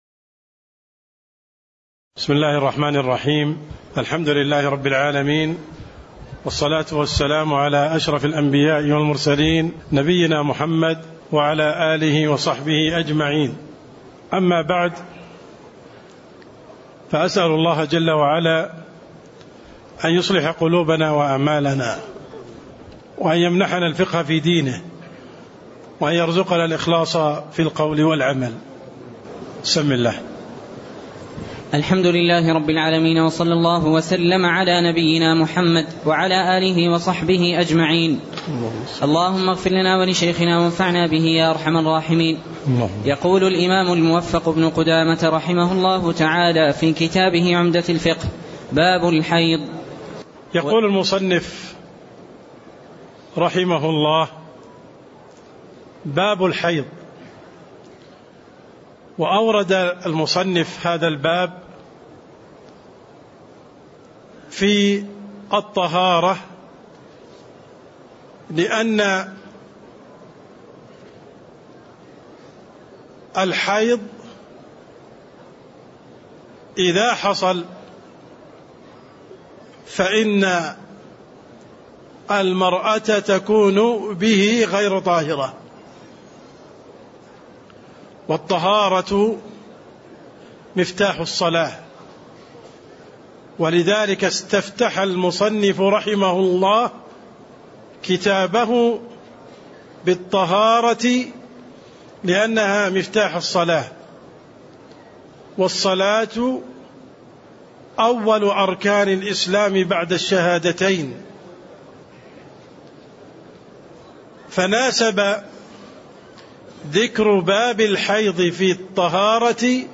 تاريخ النشر ٢٦ شعبان ١٤٣٥ هـ المكان: المسجد النبوي الشيخ: عبدالرحمن السند عبدالرحمن السند باب الحيض (12)